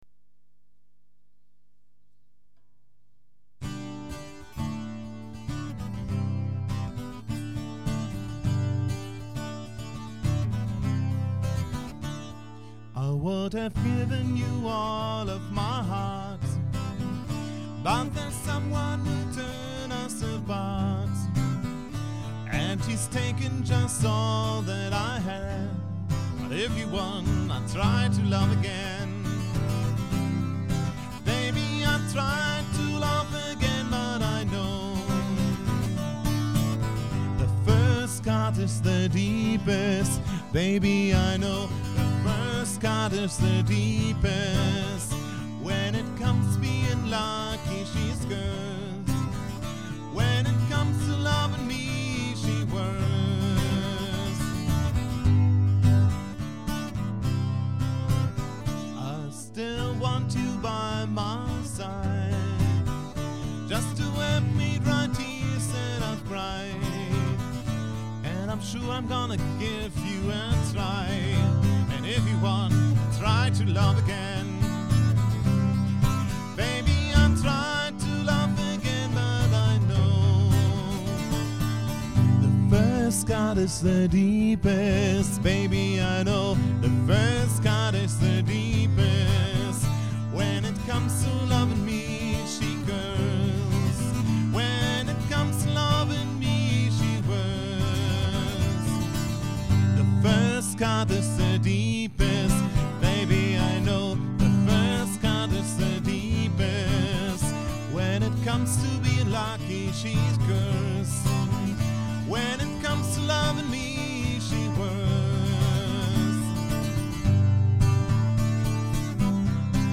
• Unplugged
• Sänger/in